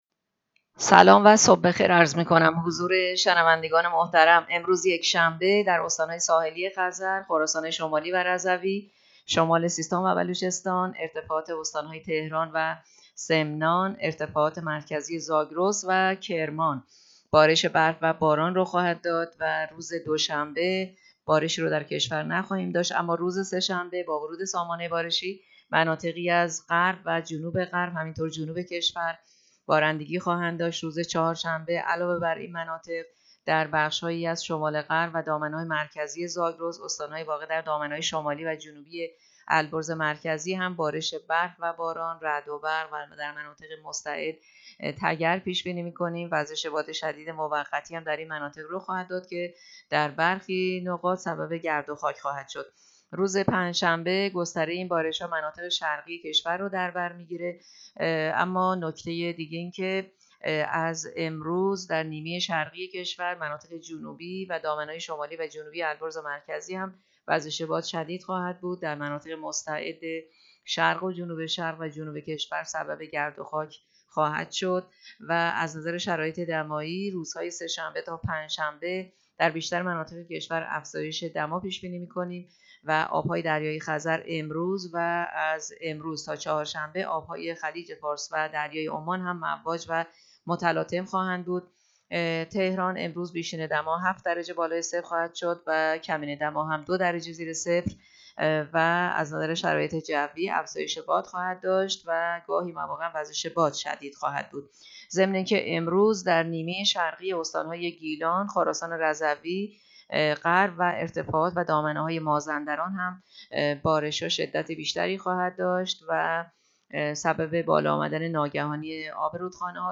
گزارش رادیو اینترنتی پایگاه‌ خبری از آخرین وضعیت آب‌وهوای ۱۲ اسفند؛